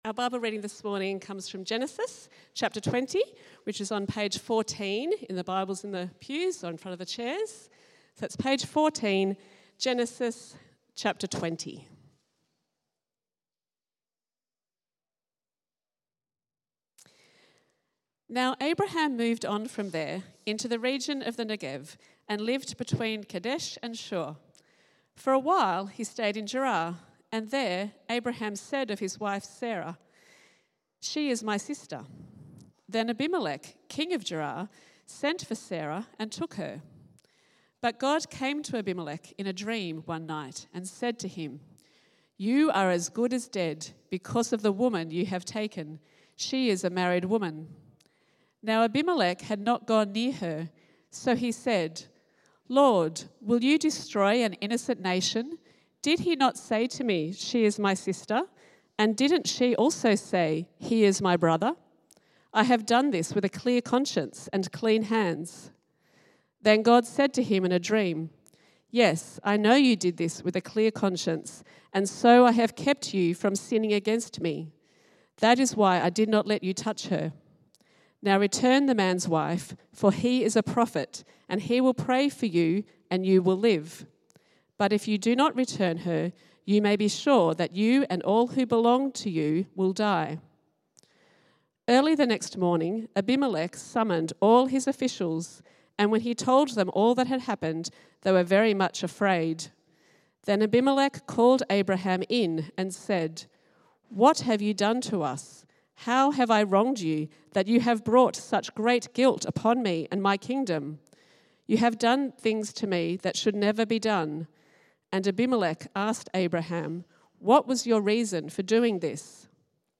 This sermon, based on Genesis 20, reflects on Abraham's recurring mistake of telling others Sarah is his sister, which causes difficult situations with foreign kings.